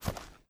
STEPS Dirt, Walk 02.wav